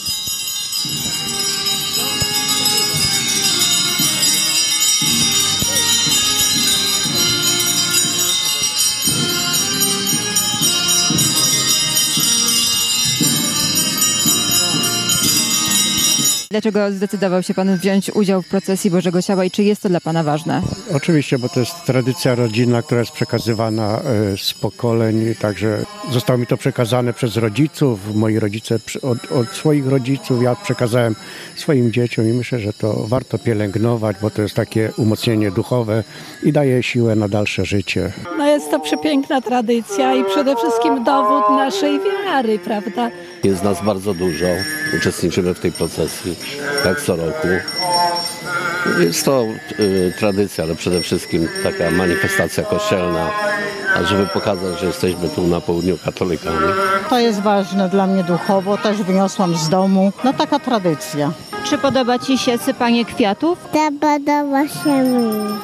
Tłumy wiernych na procesji Bożego Ciała w Leżajsku
boze-cialo-procesja-lezajsk-obrazek.mp3